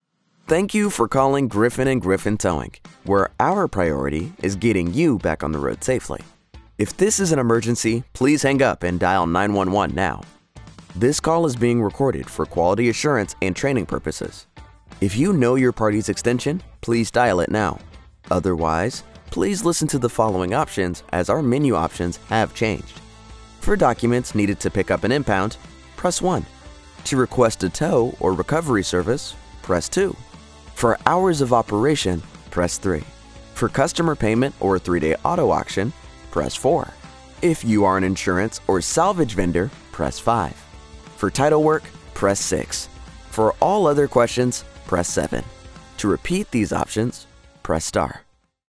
English (American)
Young, Urban, Cool, Versatile, Friendly
Telephony